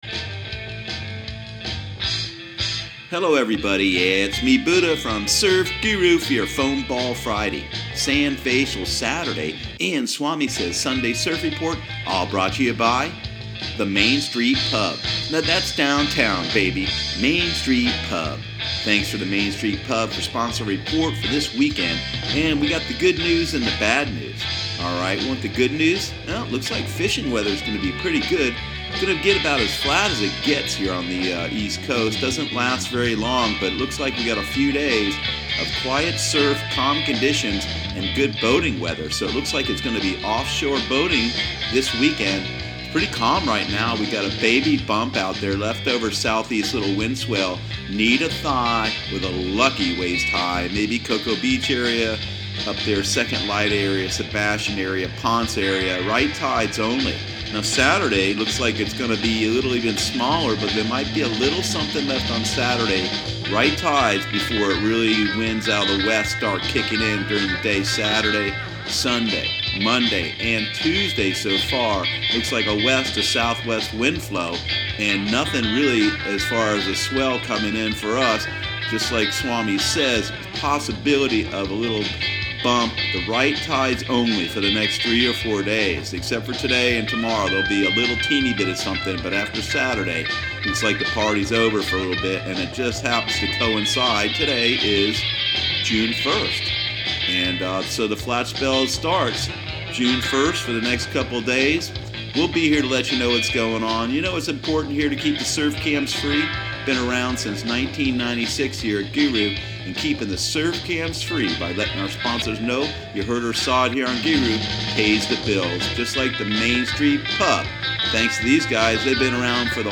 Surf Guru Surf Report and Forecast 06/01/2018 Audio surf report and surf forecast on June 01 for Central Florida and the Southeast.